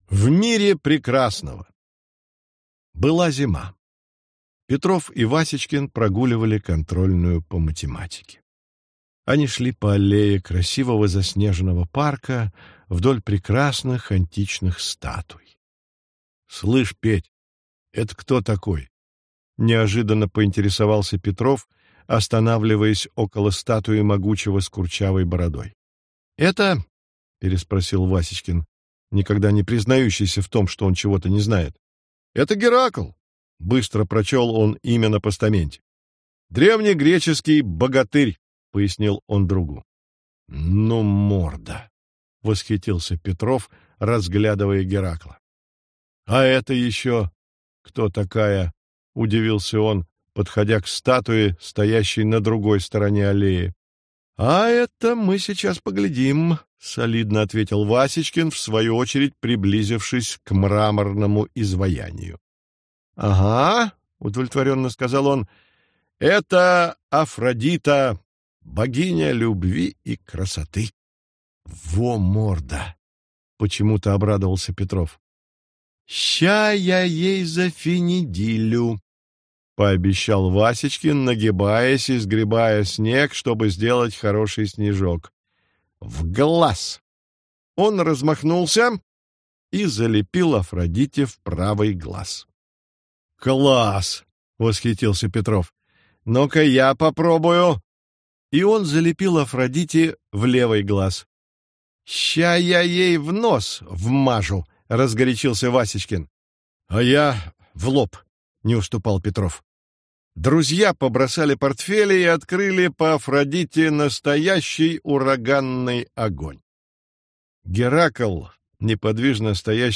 Аудиокнига Веселые истории про Петрова и Васечкина | Библиотека аудиокниг